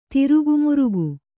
♪ tirugu murugu